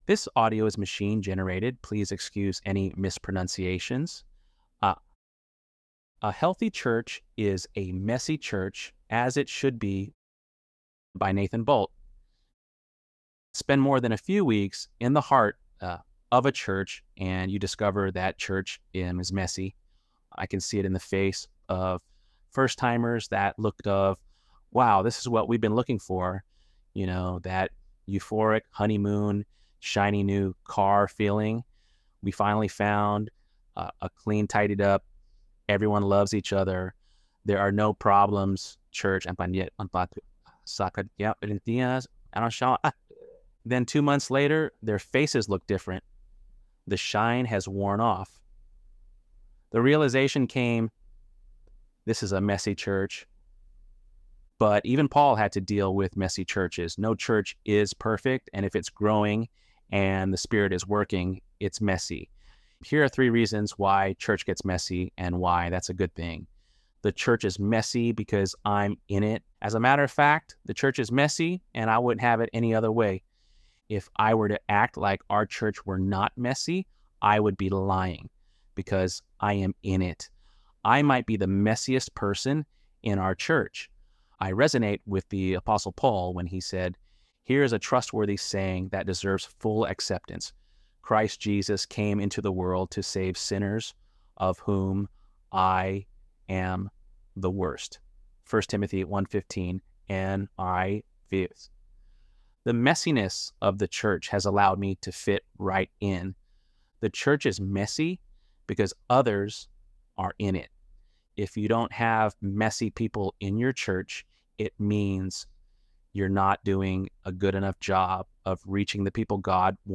ElevenLabs_2_14-1.mp3